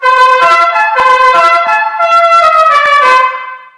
Astromovida/audio/sfx/cucaracha.wav at main
cucaracha.wav